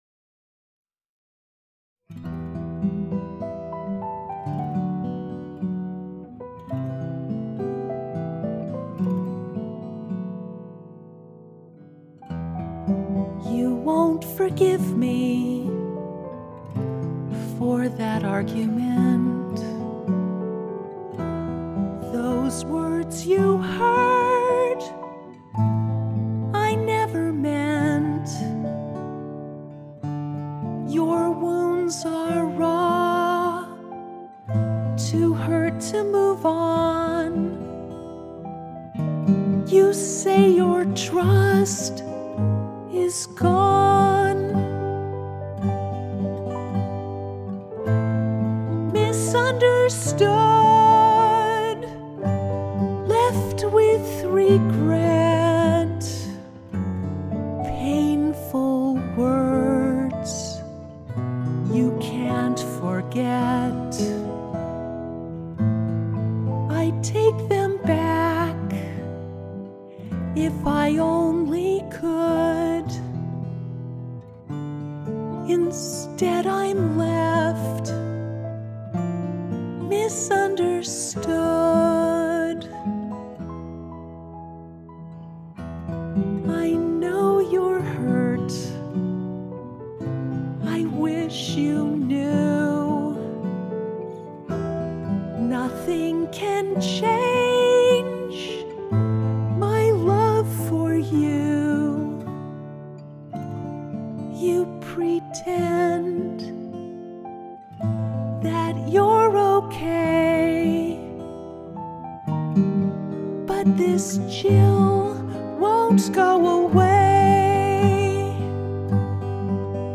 Misunderstood Acoustic 7-20-18
I felt the emotion and vulnerability pour from me as I sang.
misunderstood-acoustic-7-20-18-p.mp3